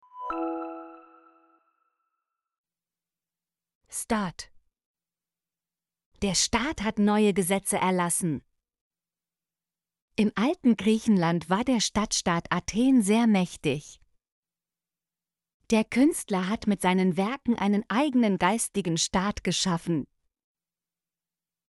staat - Example Sentences & Pronunciation, German Frequency List